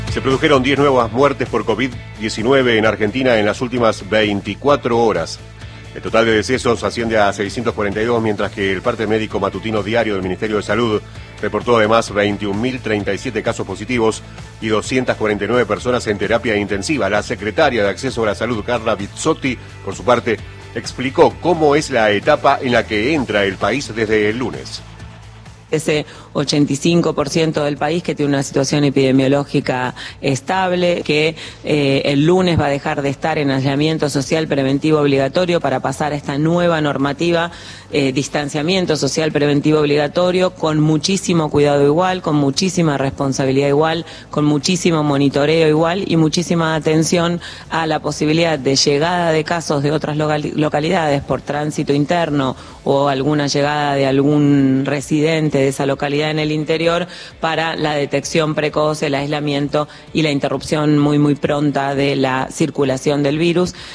Es un 85% que dejará la etapa de aislamiento para pasar a una etapa de distanciamiento " "El otro 15% seguirá en el aislamiento preventivo", dijo la secretaria de Acceso a la Salud, Carla Vizzotti, en conferencia de prensa.